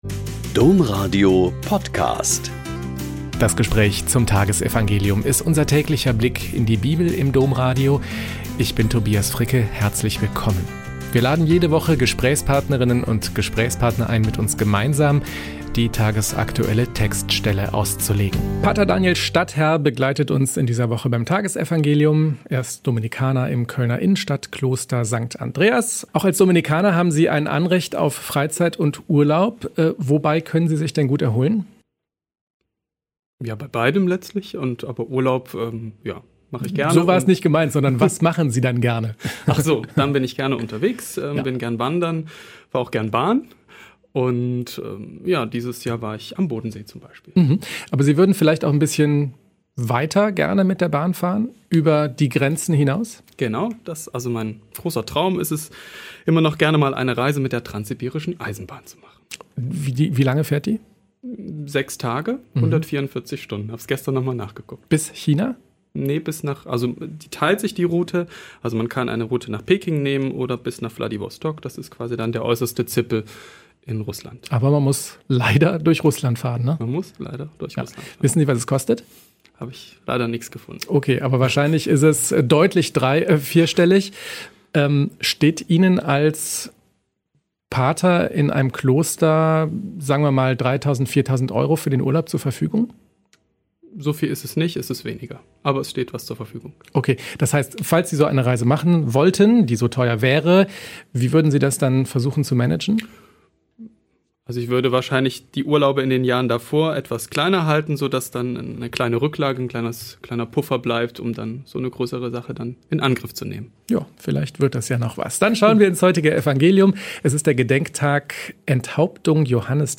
Mk 6,17-29 - Gespräch